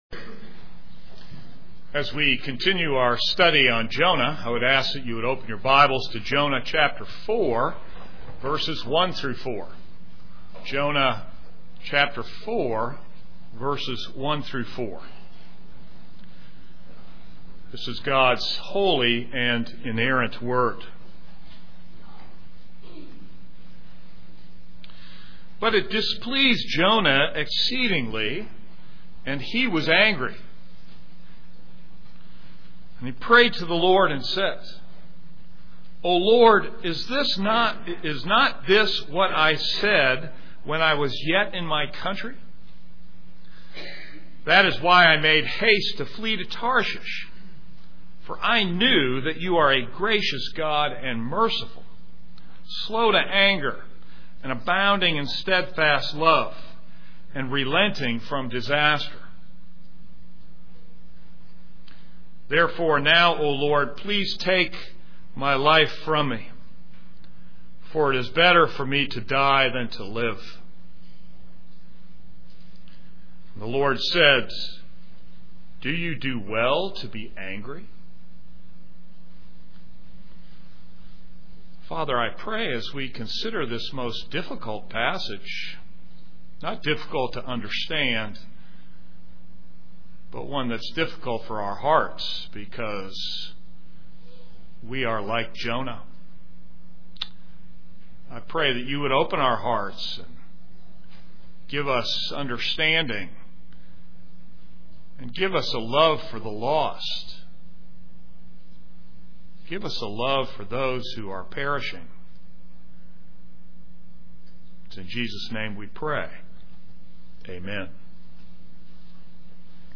This is a sermon on Jonah 4:1-4.